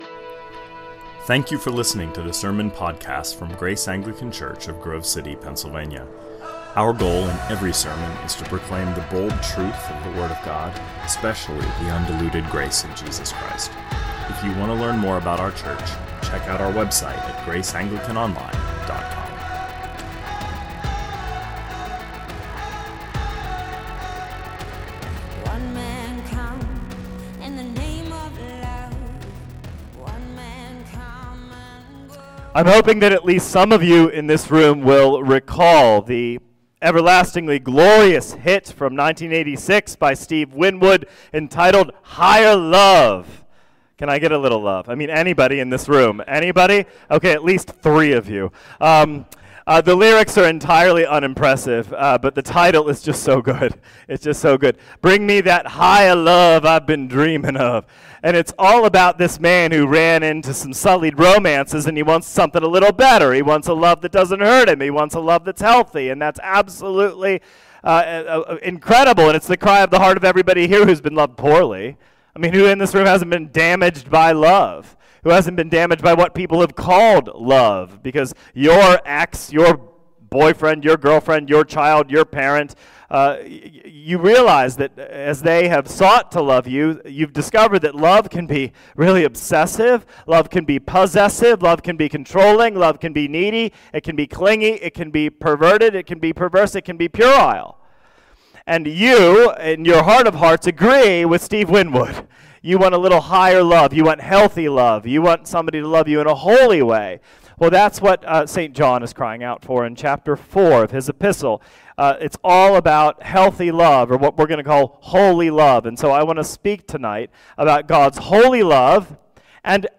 2024 Sermons Higher Love on the Streets of Tampa -John 14 Play Episode Pause Episode Mute/Unmute Episode Rewind 10 Seconds 1x Fast Forward 30 seconds 00:00 / 32:39 Subscribe Share RSS Feed Share Link Embed